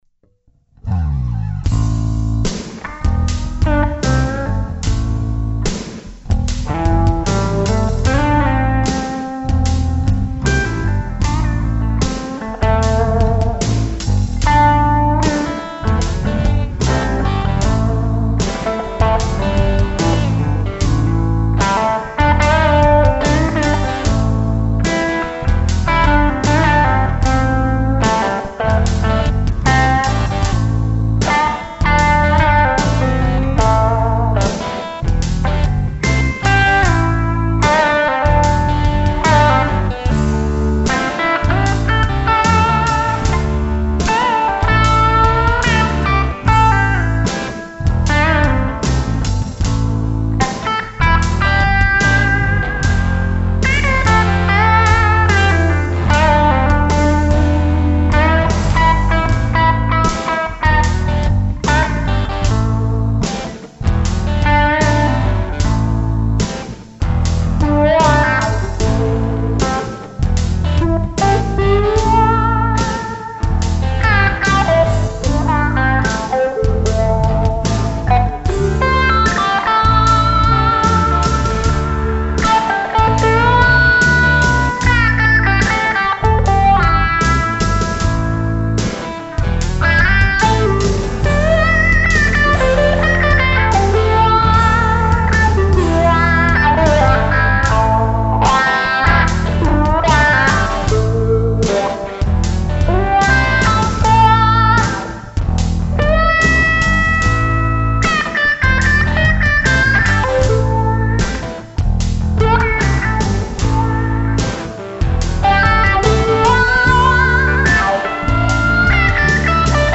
der Wah Part is ja so richtig mein Fall.
Sehr schön... die Tokai muss ich Dir wohl noch irgendwie stehlen - klingt fein das Ding ;-)
Kein Wunder, schon seit Wochen liegen backing und meine Version hier rum, aber ich soll ja nicht so schnell und bemühe mich auch.
: Eingespielt in einem Rutsch als first take mit meiner Tokai Gold Top (Duncan Seth Lovers und Papier in Öl-Kondensatoren *g*) und dem Pod, kein Schnickschnack, nachher kommt nur etwas Wah dazu.